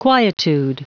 Prononciation du mot quietude en anglais (fichier audio)
Prononciation du mot : quietude